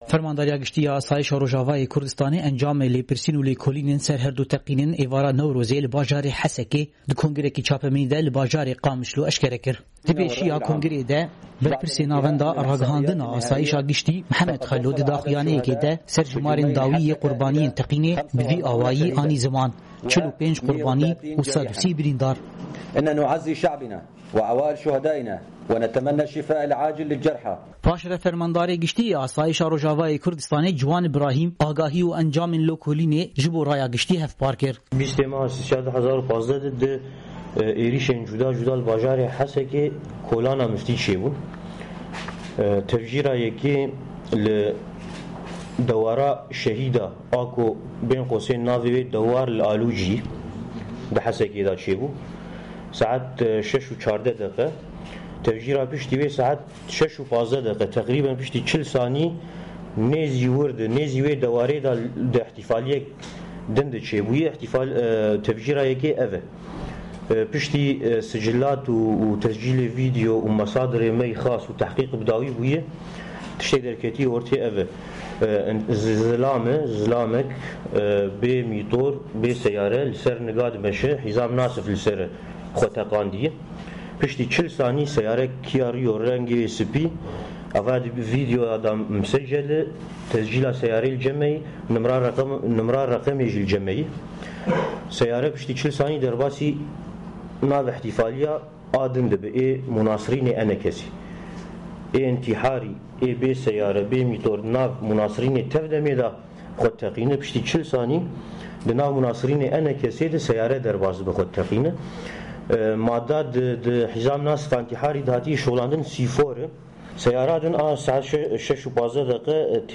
Konferansa Rojnamevanî Derbarê Teqînên Hesekê